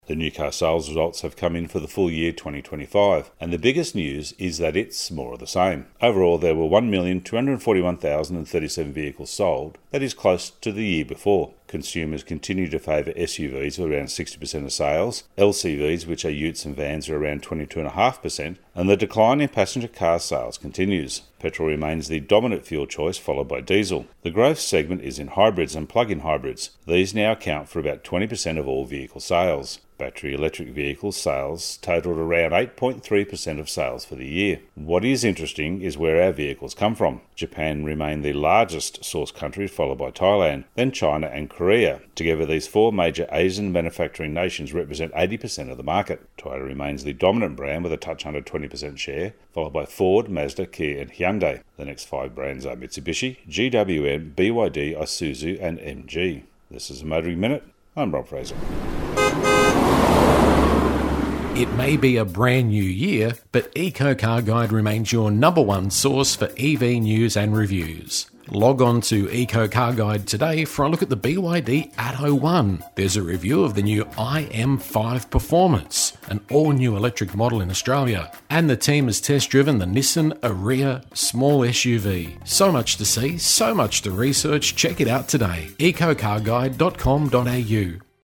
Radio Segments Motoring Minute